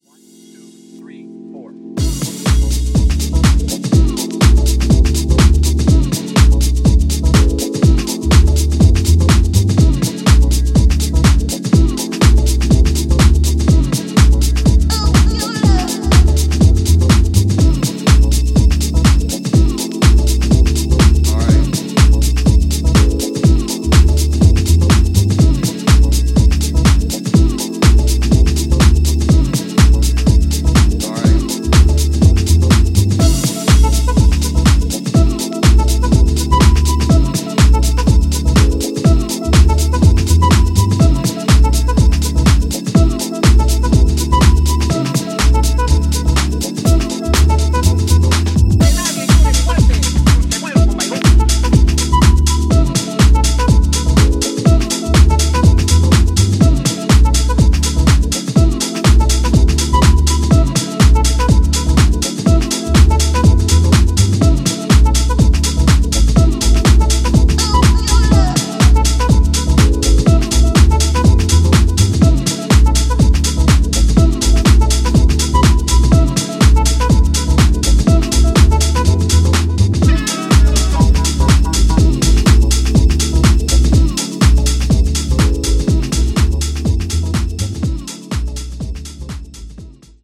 ジャンル(スタイル) HOUSE / DEEP HOUSE